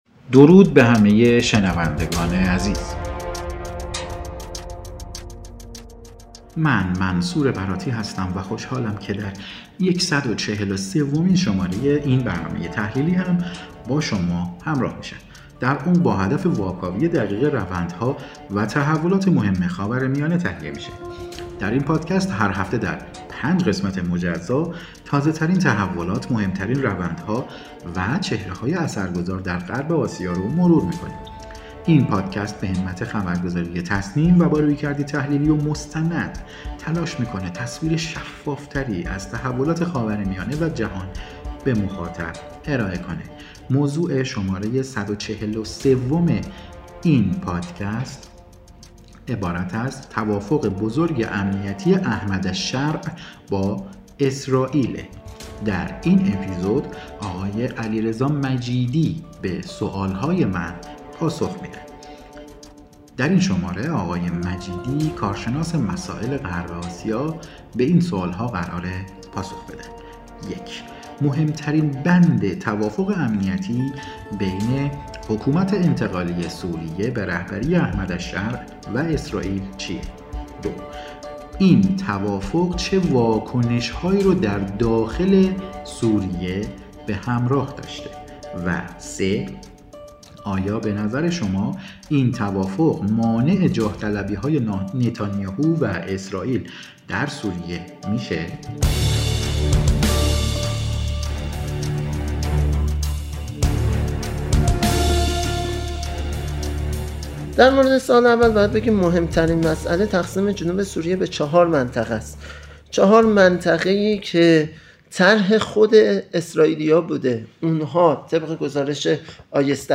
کارشناس مسائل خاورمیانه است.